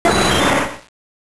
サウンド素材「ポケモン鳴き声」